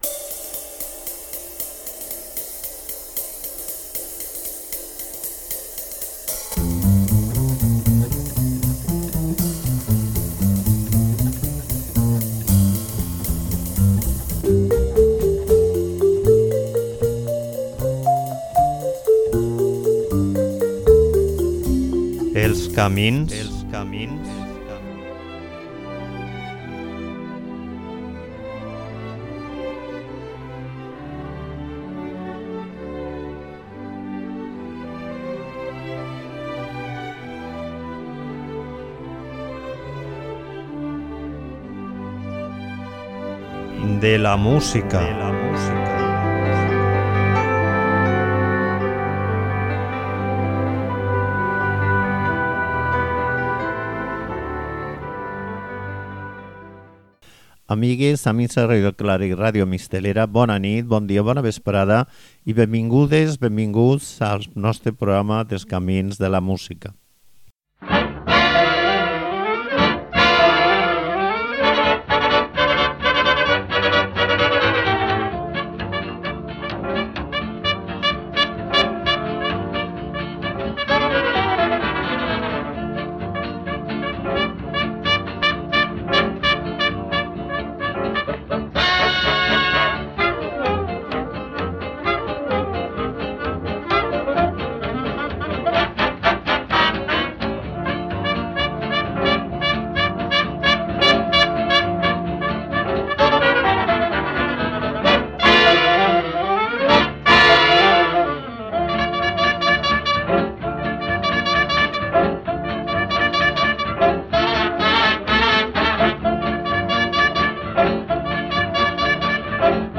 Músic negre, multi-instrumentista -encara que es solia dedicar sols a dirigir-, va tindre l'encert i la sort de agrupar una sèrie de músics que, tant en l'aspecte instrumental com en el compositiu i dels arranjaments, li varen transmetre a l'orquestra un caire especial que encantava als públics.